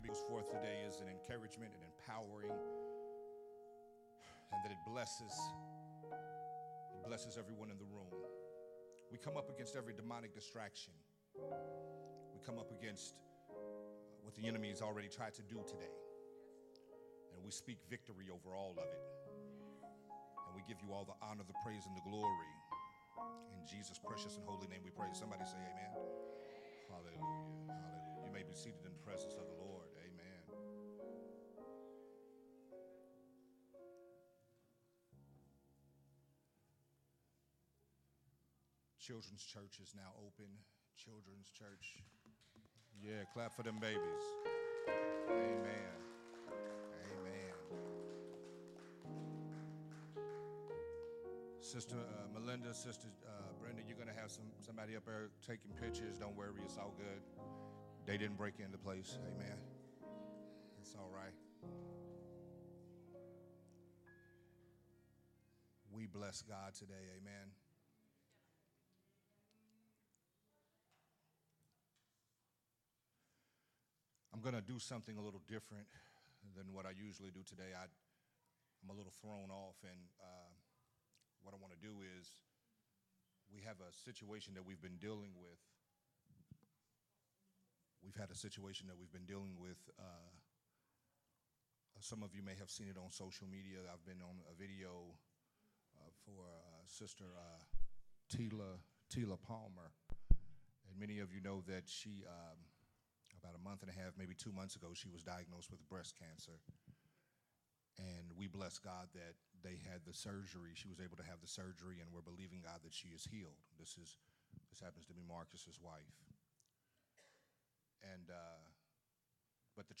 a Sunday Morning Worship Service message
recorded at Unity Worship Center on July 23rd, 2023.